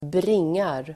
Uttal: [²br'ing:ar]